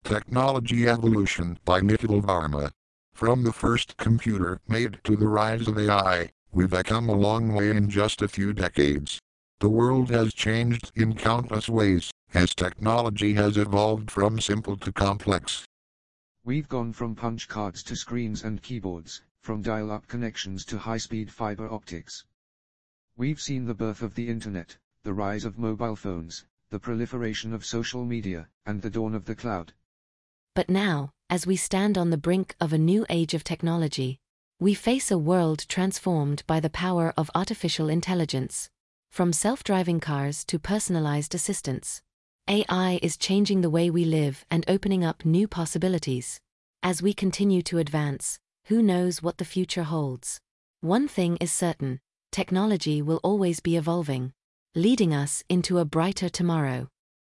To highlight the evolution of these voices the following poem titled Technology Evolution is voiced by three Microsoft voices: Sam which was the default voice on Windows XP, George which is a Windows 11 voice, and Seraphina which is an AI voice available through Microsoft Azure AI Speech Studio.
The voices in the clip were created using default settings, with additional work it’s possible to improve the output.
The audio clip linked above clearly demonstrates that synthetic speech has seen remarkable advancement over the years, from a very robotic voice to a more advanced clear and fluent quality voice.